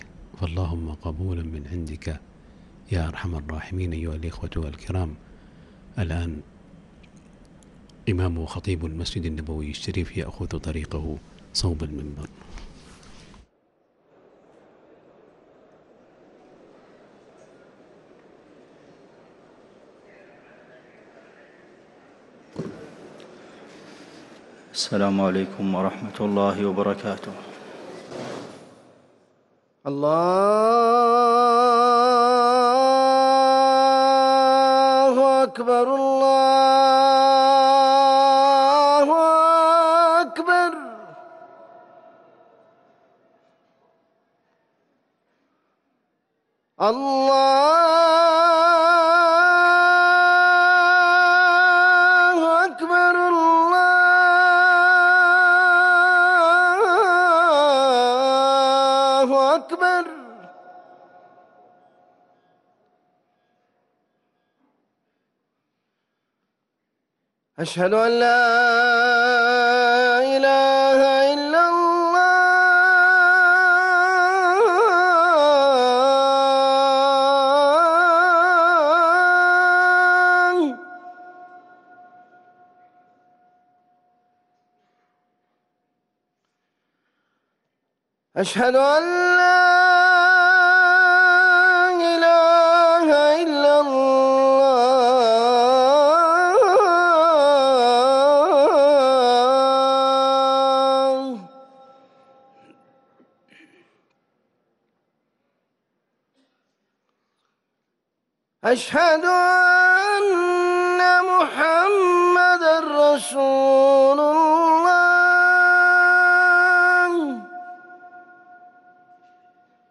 أذان الجمعة الثاني